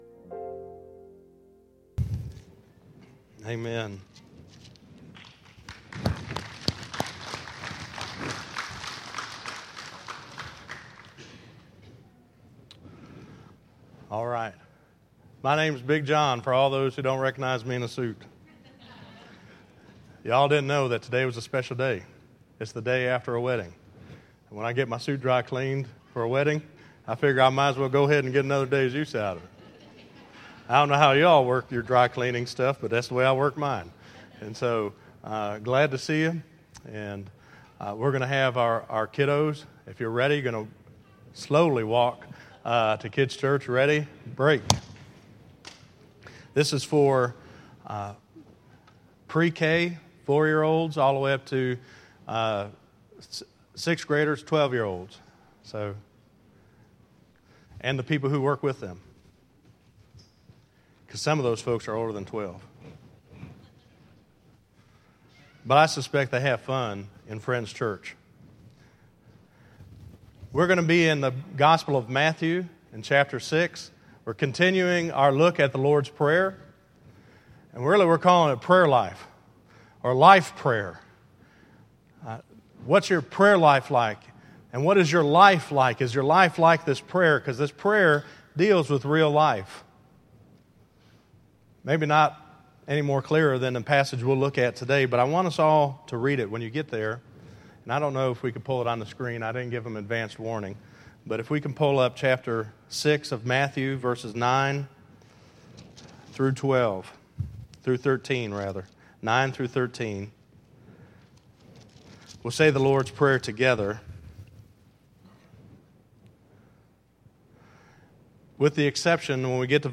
Listen to Prayer Life = Life Prayer 3 - 06_08_2014_Sermon.mp3